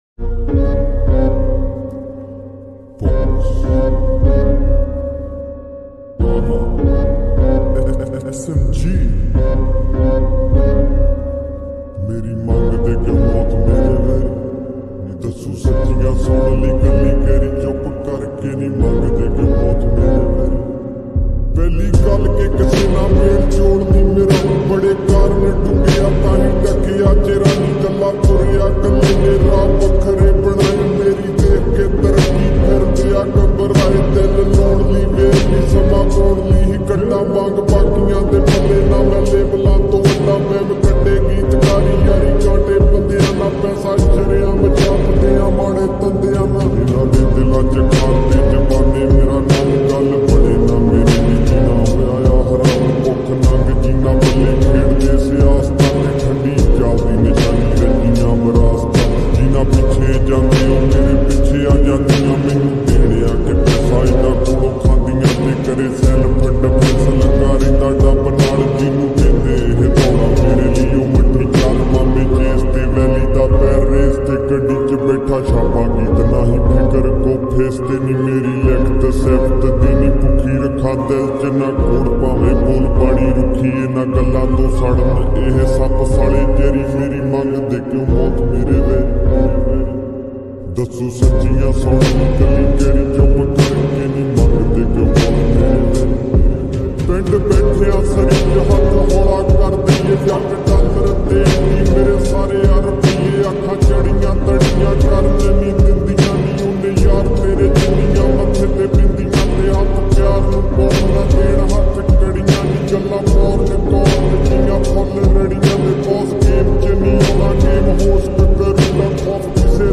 slowed down reverb